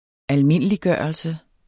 Udtale [ -ˌgɶˀʌlse ]